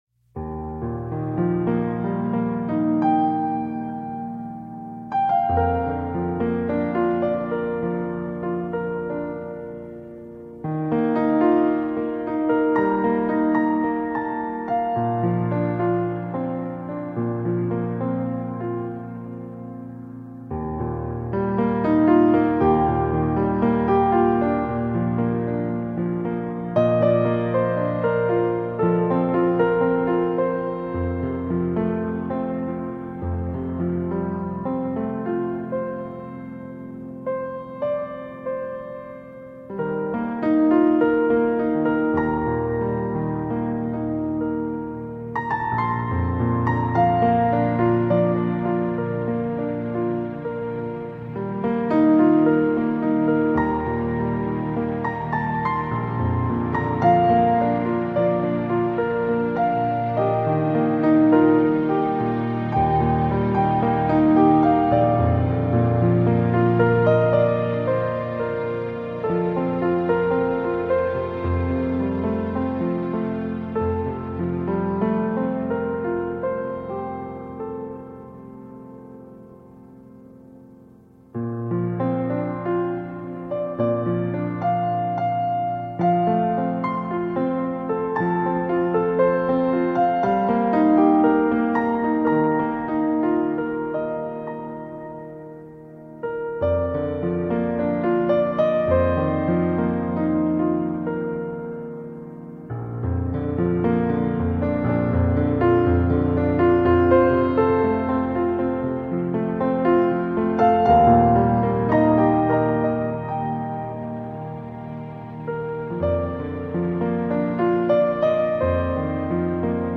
Душевная,созвучная музыка.
Мелодия приятная...))